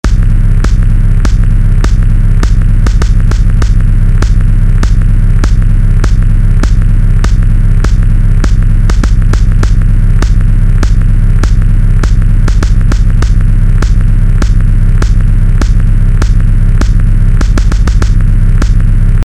Und mit diesen Effekten …